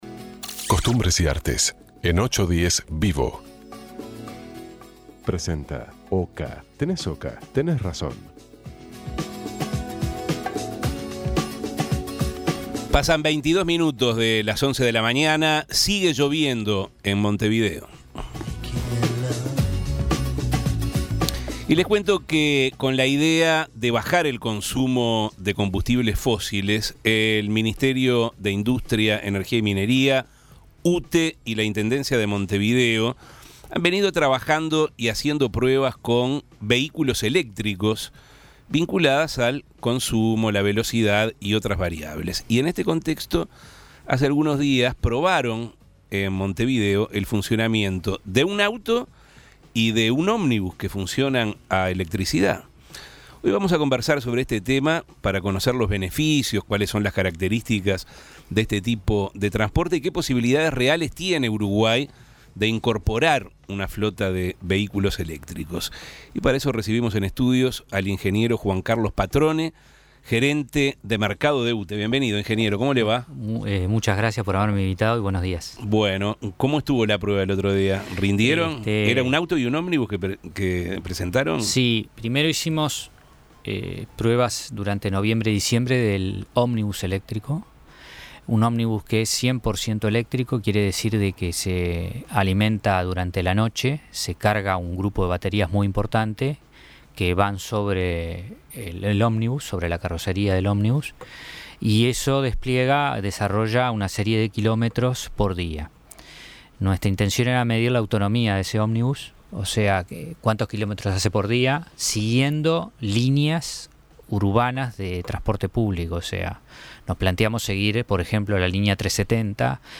Con el fin de reducir el consumo de combustibles fósiles, el Ministerio de Industria, Energía y Minería, UTE y la Intendencia de Montevideo están realizando pruebas con vehículos eléctricos. Para conocer los beneficios de este tipo de transporte y las posibilidades reales de que Uruguay tenga una flota de vehículos eléctricos, 810 Vivo Avances, tendencias y actualidad recibió en estudios